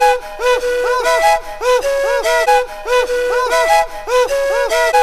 AFRIK FLUTE6.wav